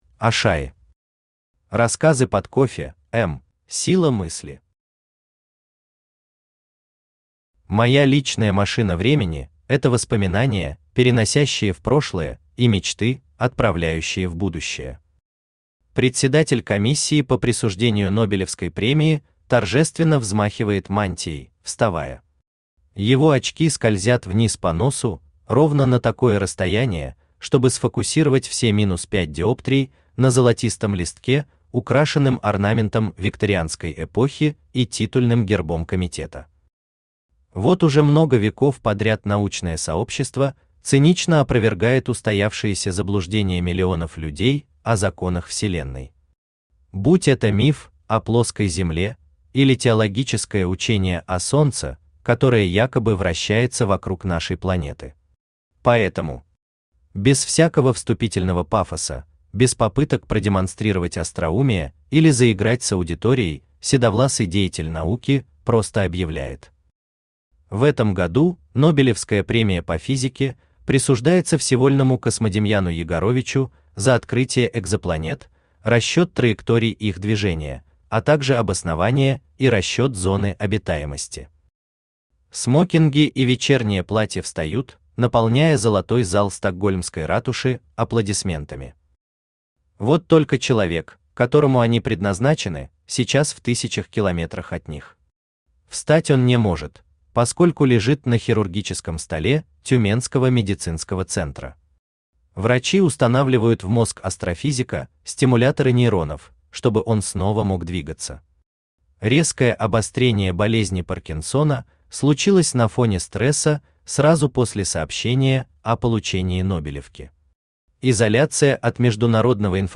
Aудиокнига Рассказы под кофе(м) Автор Ашаи Читает аудиокнигу Авточтец ЛитРес.